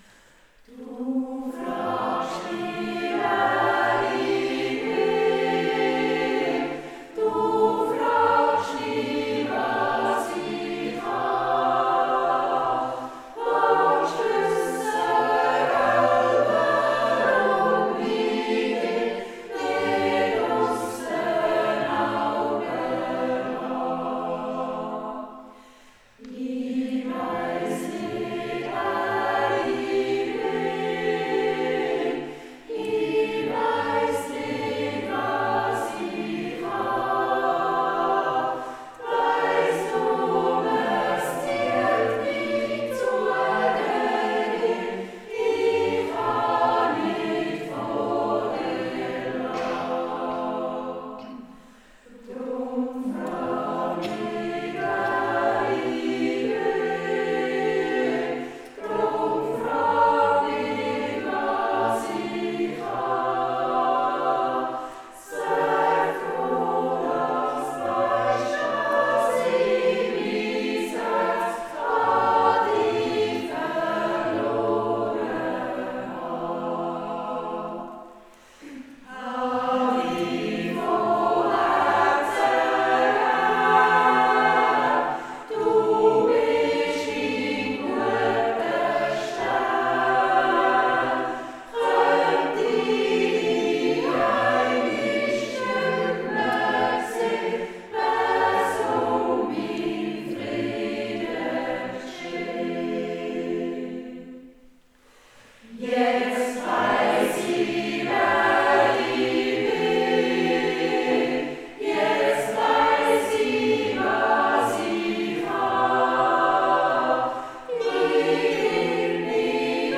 Seit 2018 ist die SingWerkstatt ein gemischter Chor, wobei die Herren erst im Januar 2024 Geschmack an uns gefunden haben.